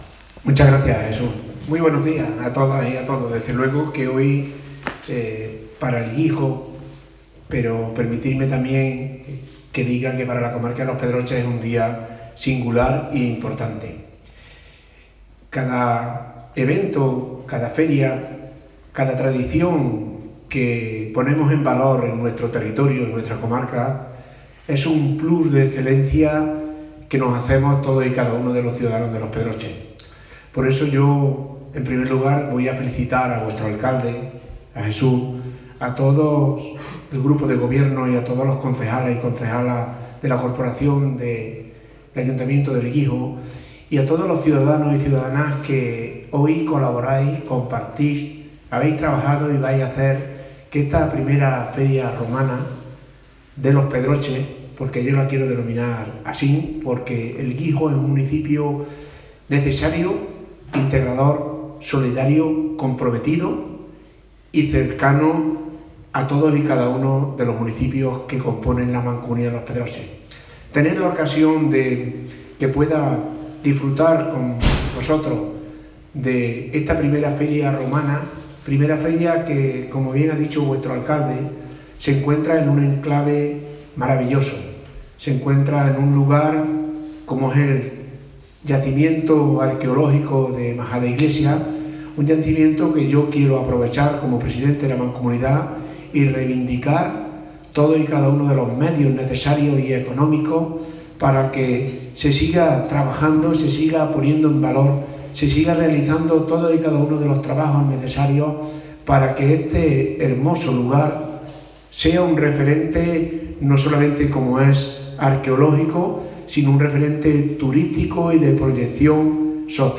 Escuchamos sus intervenciones:
Jesús Fernández, alcalde